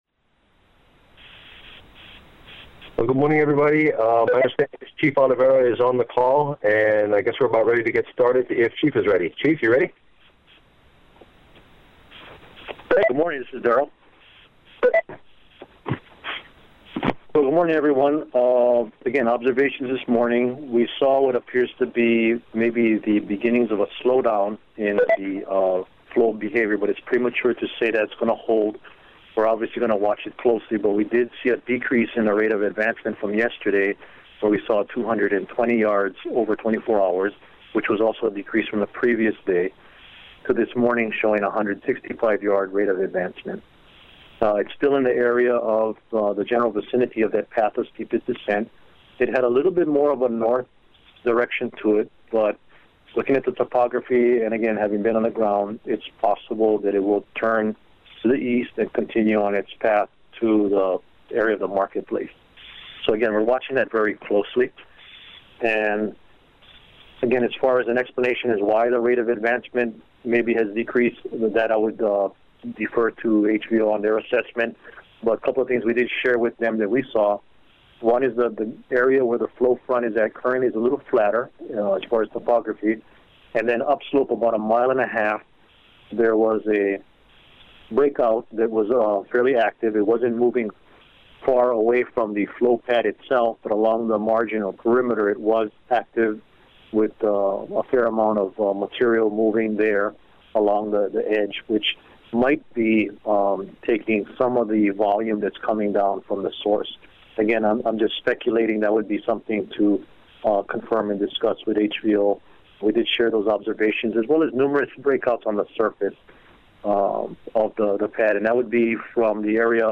Here is audio from today’s conference call at 11:30 p.m. HST:
1218confcall.mp3